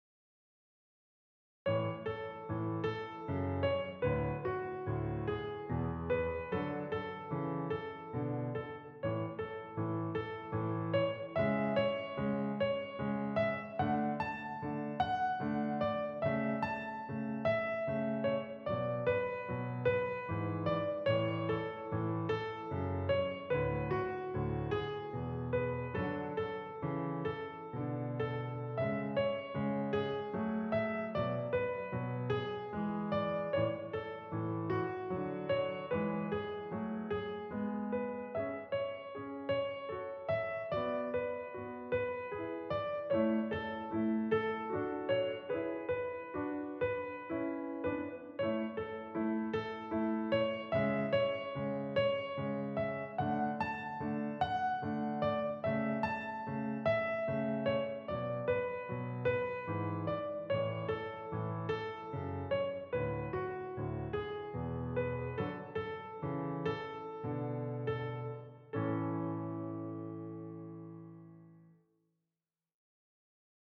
Twinklepated is a syncopated variation of Twinkle Twinkle Little Star.
This Twinkle etude – Twinklepated is set in 3/4 time matching the time signature of the Boccherini Minuet.
Twinklepated – A Major Accompaniment Track